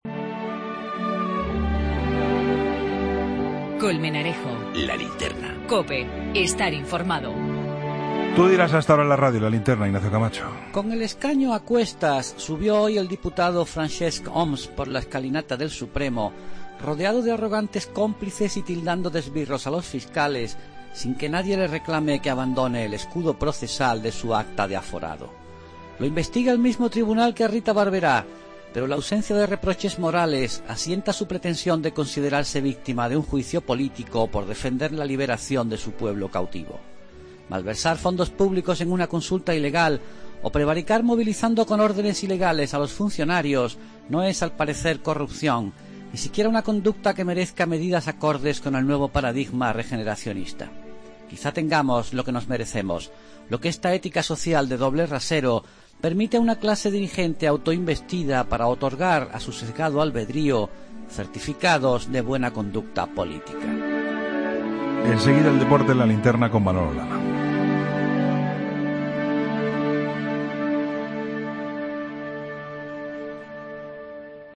'El comentario de Ignacio Camacho' en 'La Linterna' va dirigido al exconsejero de Presidencia de la Generalitat de Cataluña, Francesc Homs, quien ha declarado en el Tribunal Supremo como investigado por la celebración de la consulta soberanista del 9 de noviembre de 2014, suspendida por el Tribunal Constitucional.